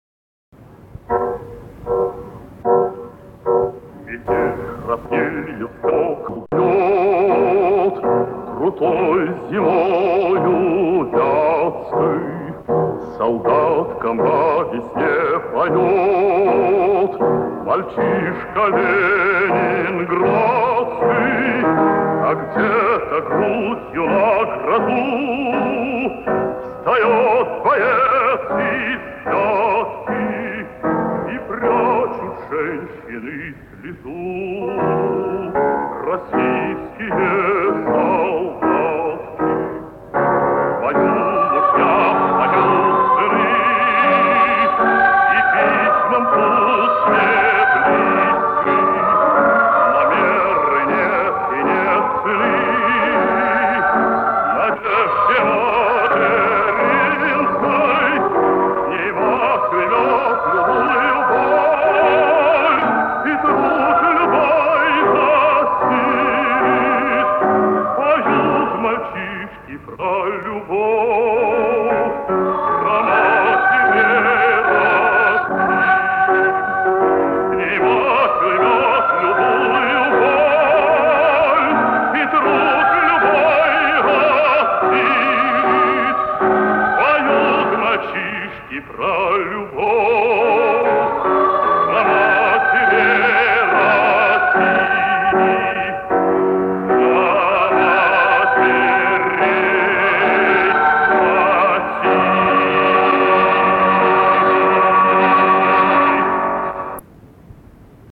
Со старой кассеты.